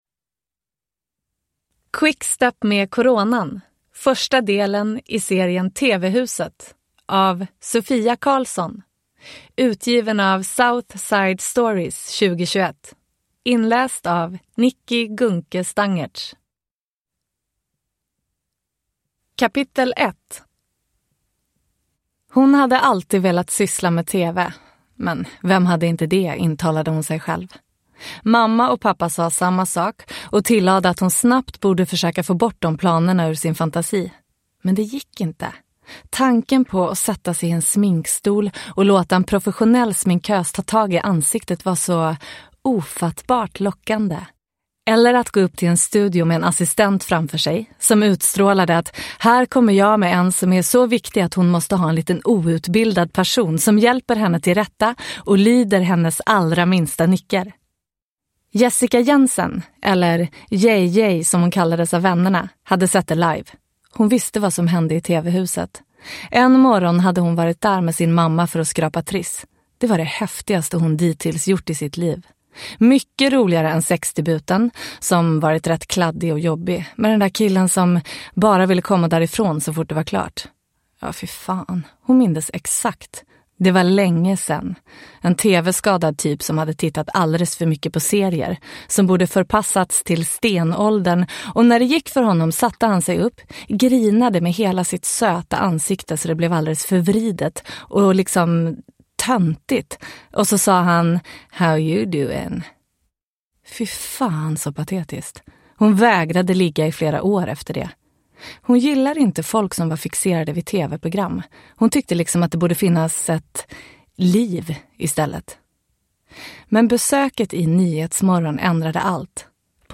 Quickstep med coronan – Ljudbok – Laddas ner